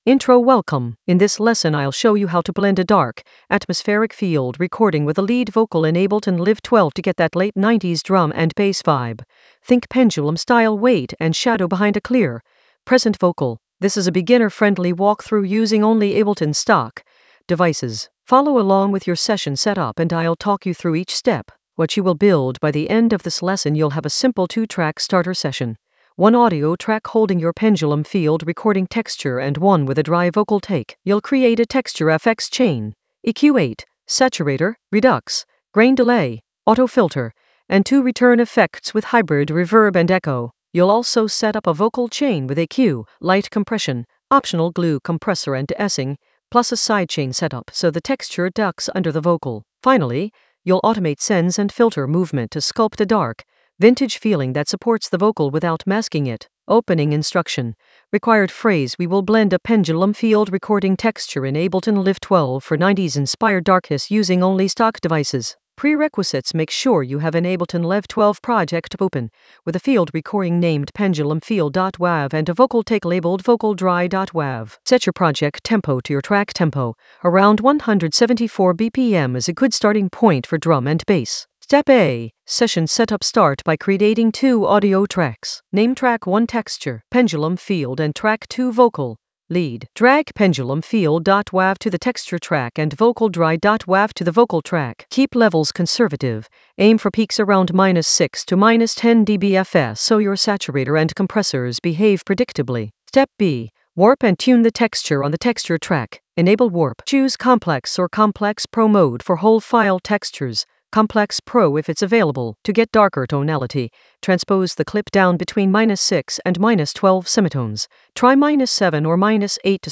An AI-generated beginner Ableton lesson focused on Blend a Pendulum field recording texture in Ableton Live 12 for 90s-inspired darkness in the Vocals area of drum and bass production.
Narrated lesson audio
The voice track includes the tutorial plus extra teacher commentary.